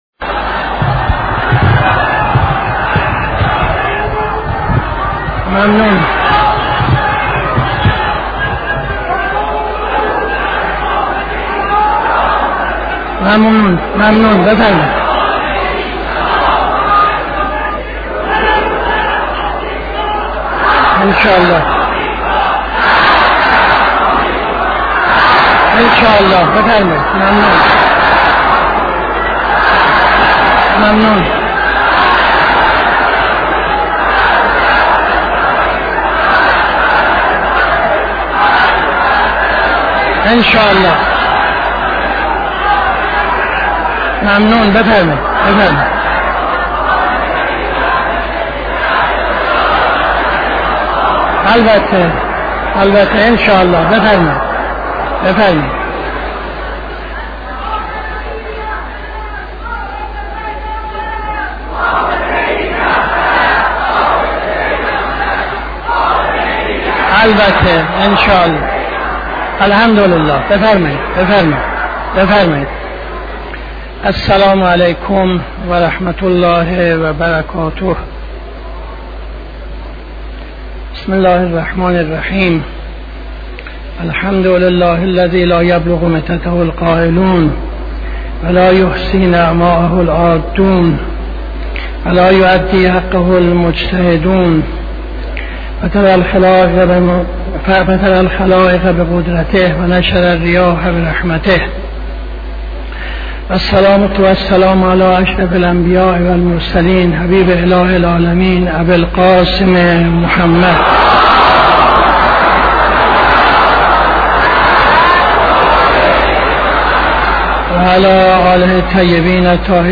خطبه اول نماز جمعه 03-12-75